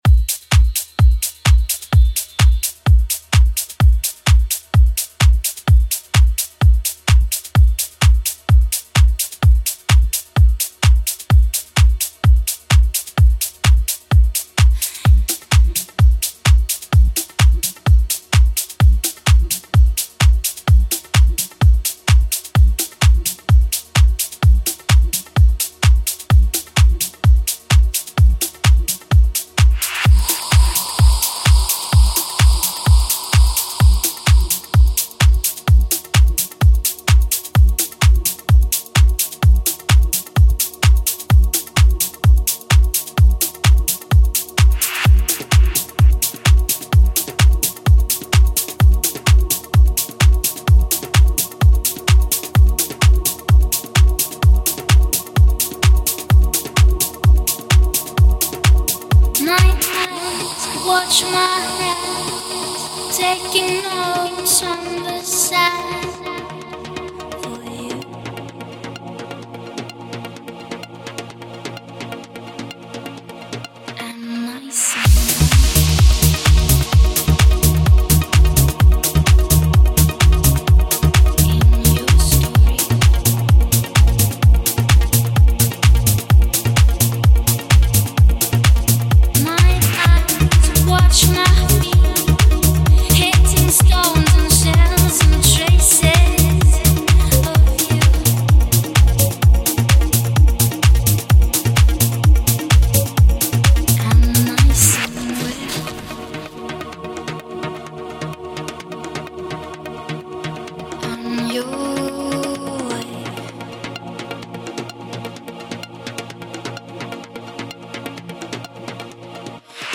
GENRE: PROGRESSIVE HOUSE
BPM: 132 BPM'S KEY: Dm